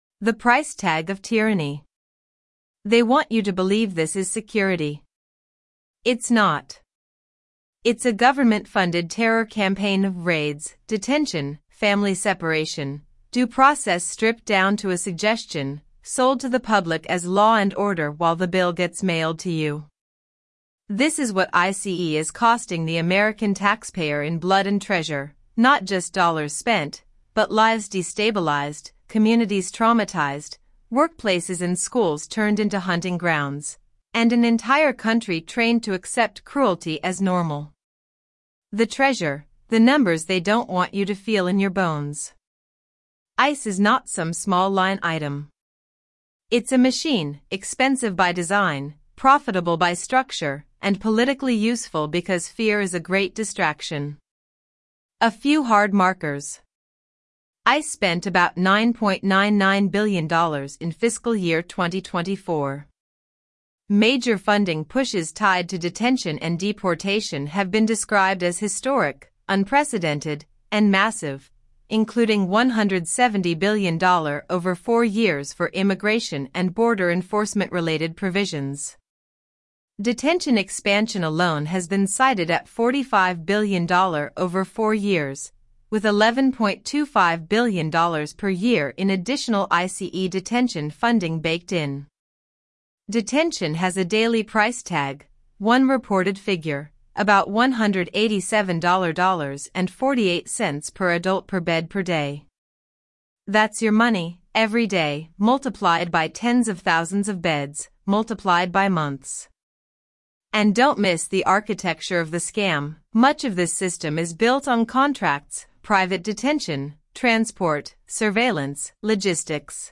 AI Editorial Assistant • ~8 minutes